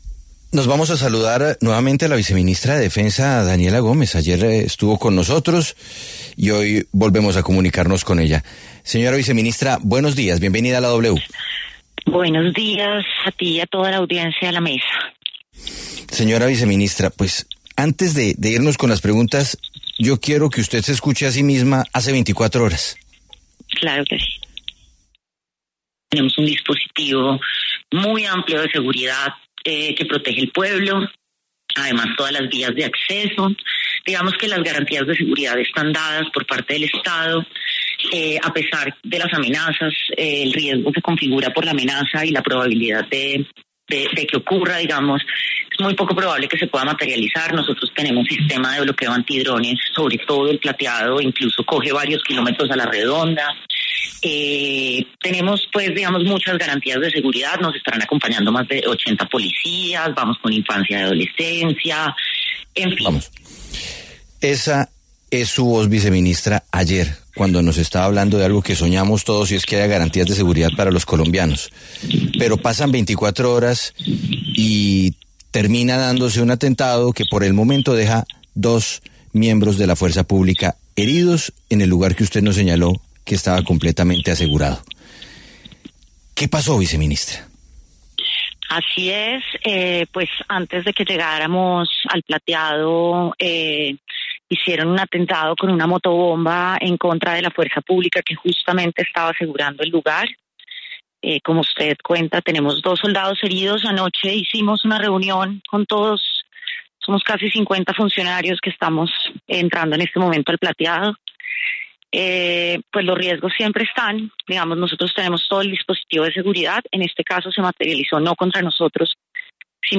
La viceministra de Defensa Daniela Gómez aseguró en La W que la oferta institucional y cultural continuará llegando a El Plateado pese a las amenazas de disidencias Farc.